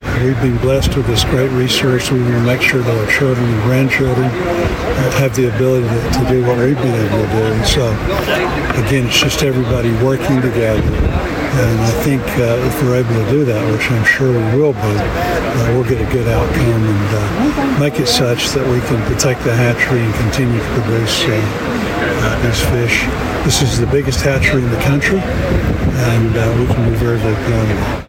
KTLO, Classic Hits and the Boot News spoke with Senator Boozman at the hatchery where he spoke on the importance of the site.